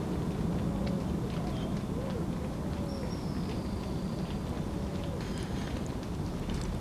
Alegrinho-trinador (Serpophaga griseicapilla)
Nome em Inglês: Straneck´s Tyrannulet
Fase da vida: Adulto
Localidade ou área protegida: Reserva Ecológica Costanera Sur (RECS)
Condição: Selvagem
Certeza: Gravado Vocal
piojito-trinador.mp3